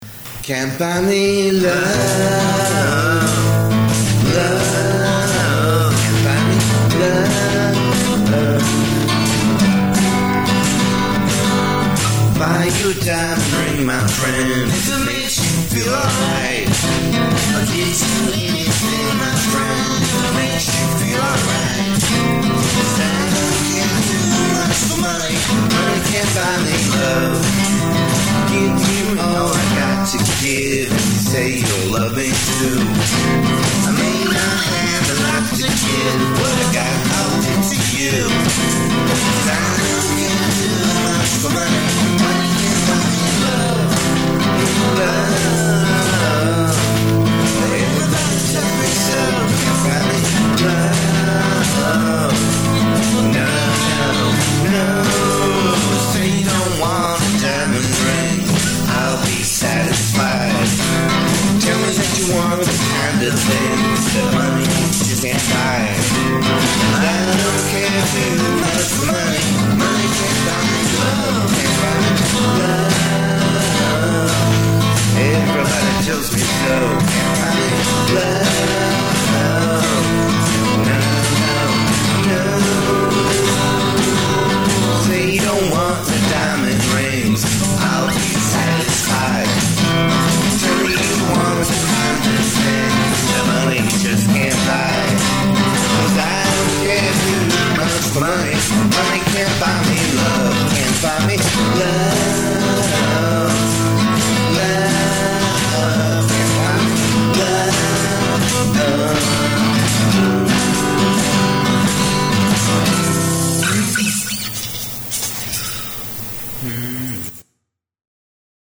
This thing is off the rail, too fast and drowsy.